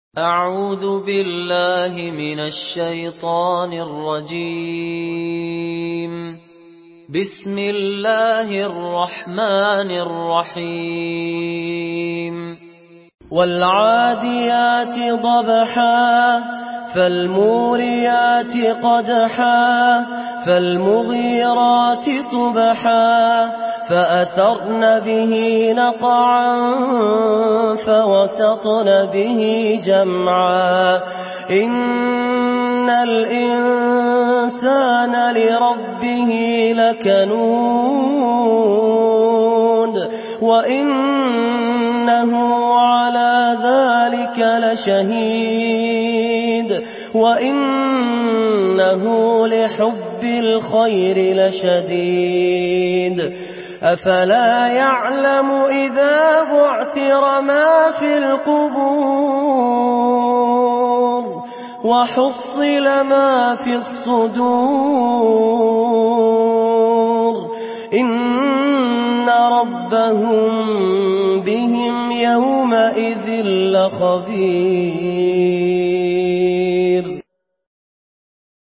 مرتل حفص عن عاصم